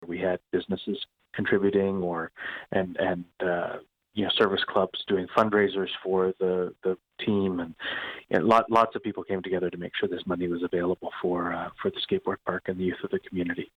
Mayor of Brighton Brian Ostrander says the project brought the community together.
brian-ostrander.mp3